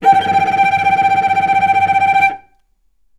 vc_trm-G5-mf.aif